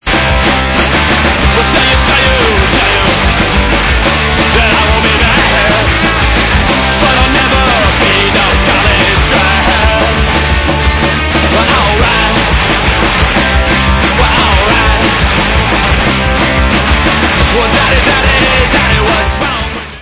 sledovat novinky v oddělení Rock/Punk